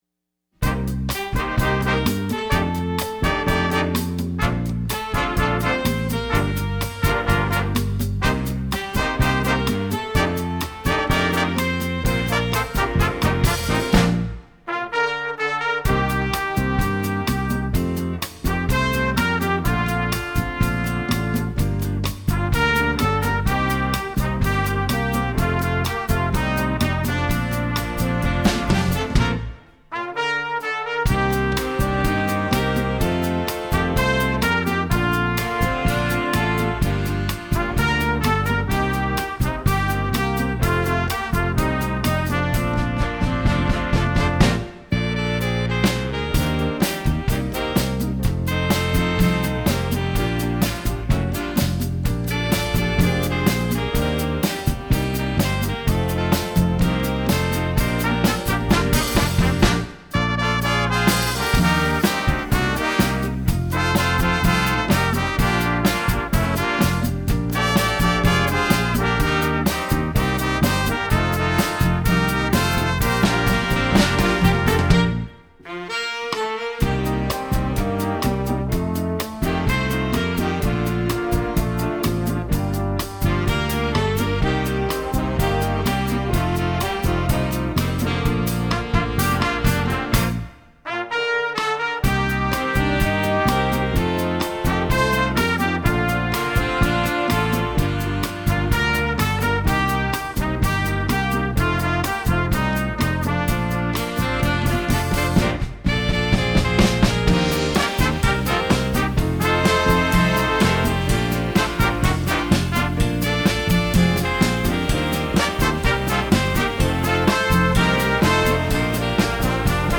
Vocal Group with Big Band
Edition: Jazz Big Band Arrangement
Description: Latin - Easy/Medium Easy
Baritone Saxophone
Piano
Drums